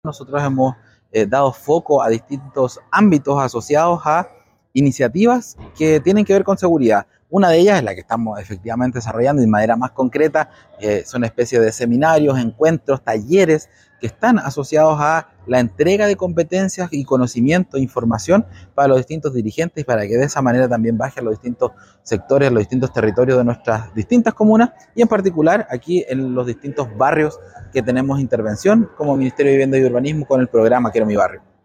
Con gran éxito se desarrolló el Primer Encuentro de Seguridad Barrial, como parte del Plan de Confianza Social del Programa Quiero Mi Barrio, en la población García Hurtado de Mendoza, en Rahue Alto.